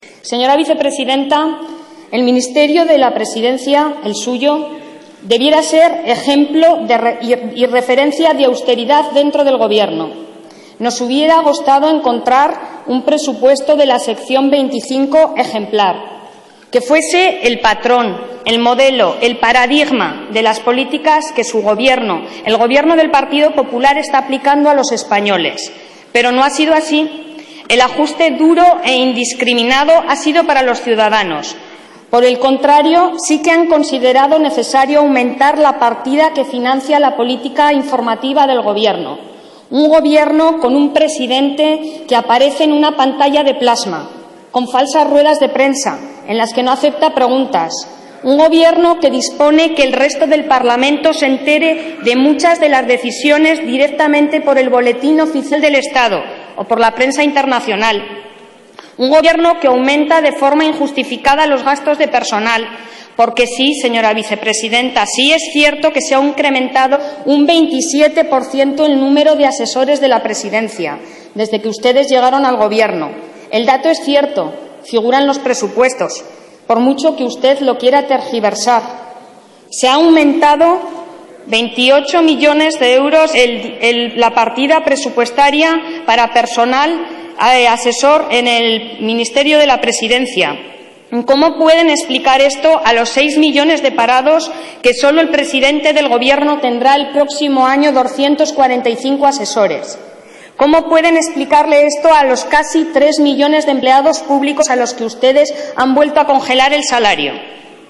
Susana Sumelzo replica a la vicepresidenta y ministra de la presidencia.